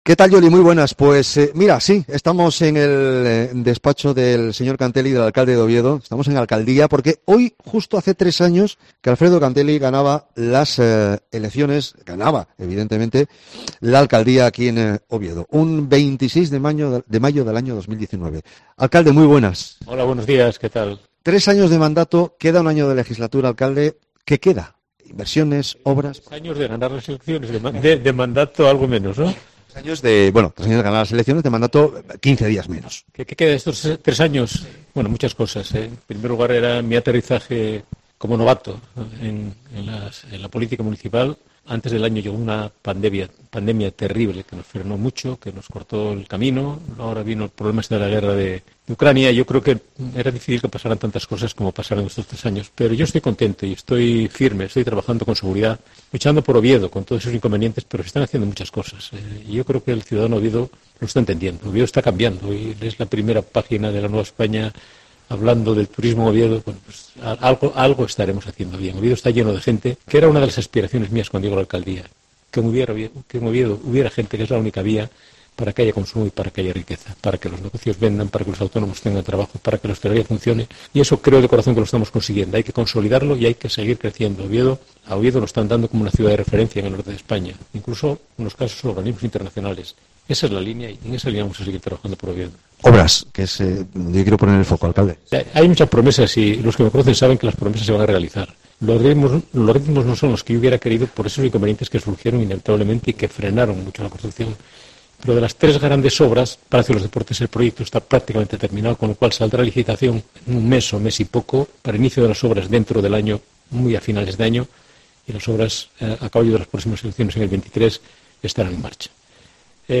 Entrevista con Alfredo Canteli